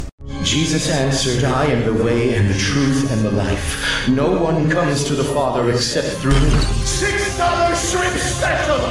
Dollar Sound Effects MP3 Download Free - Quick Sounds